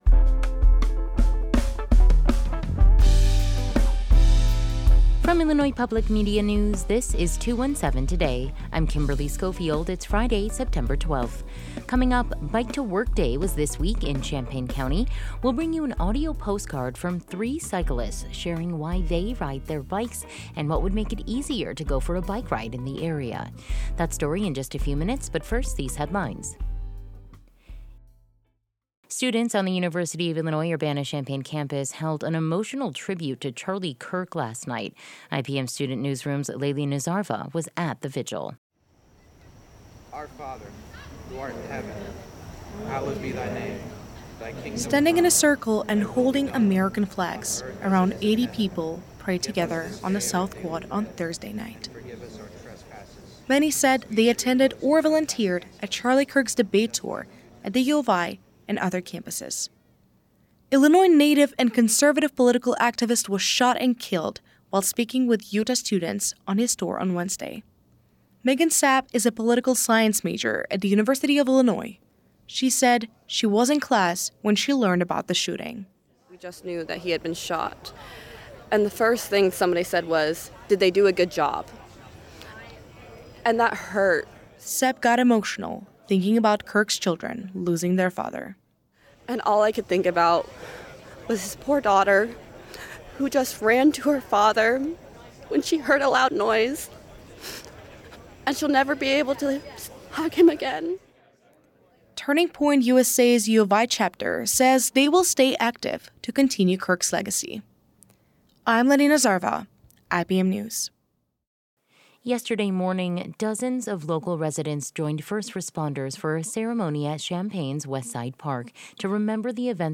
In today’s deep dive, Bike to Work Day was this week in Champaign County. Three cyclists share why they ride their bikes and what would make it easier to go for a bike ride in the area.